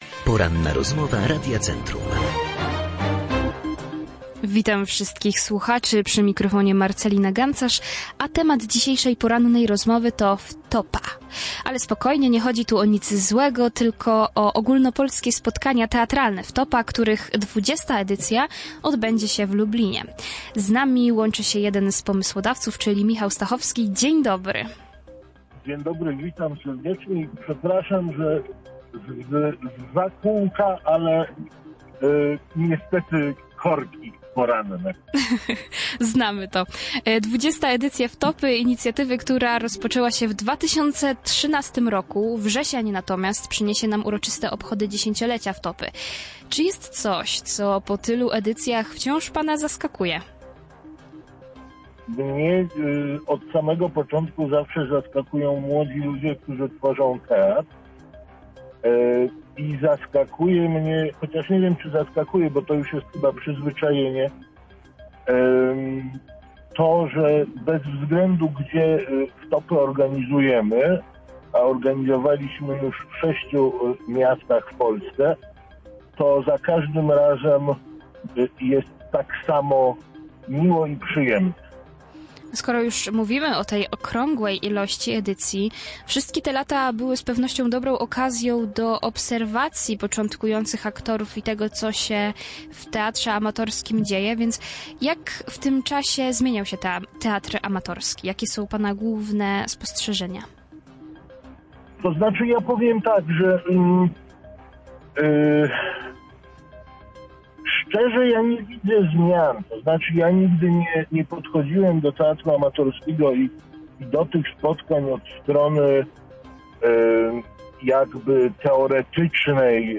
O głównych założeniach inicjatywy mówiliśmy podczas Porannej Rozmowy Radia Centrum.